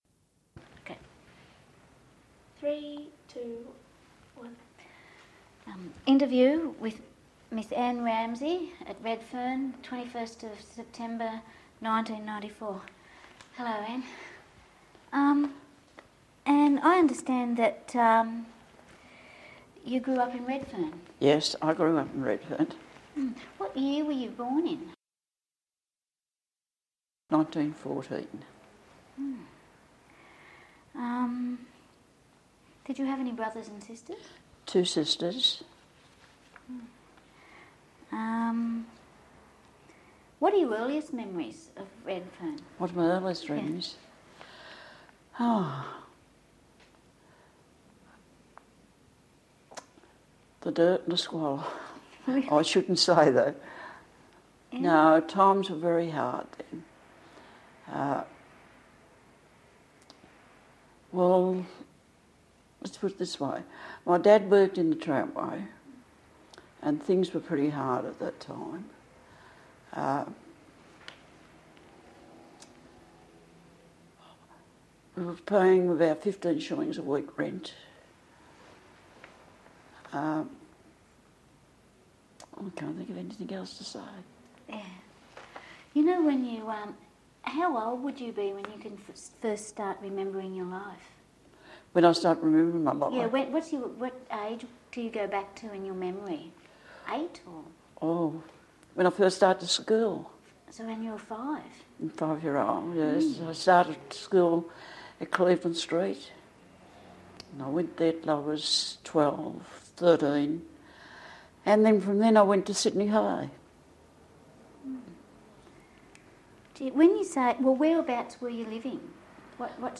This interview is part of the City of Sydney's oral history project: Life in c20th South Sydney